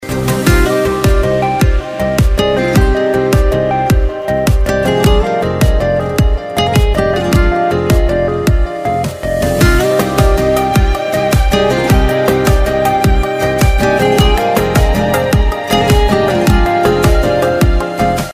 رینگتون ملایم و بی کلام